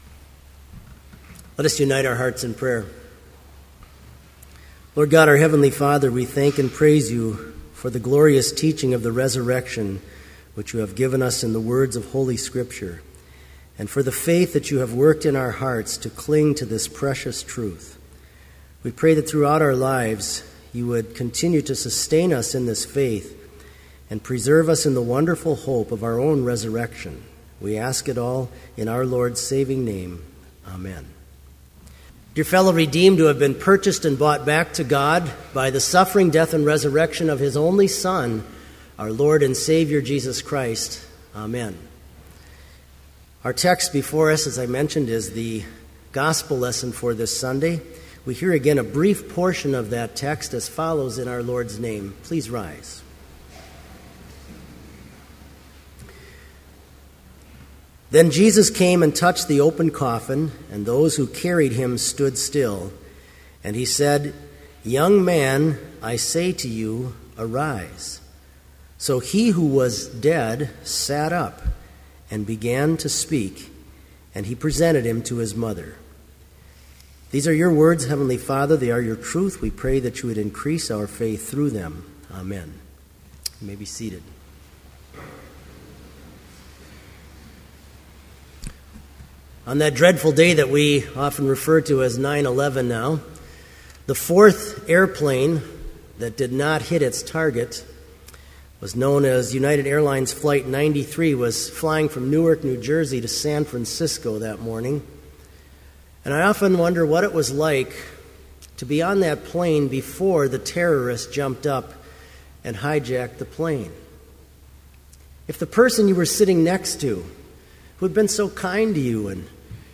Special service on September 23, 2012, at Bethany Chapel in Mankato, MN,
Complete service audio for Fall Festival Matins - September 23, 2012
(All may join with the choir to sing Hymn 69, vv. 1 & 8)
Homily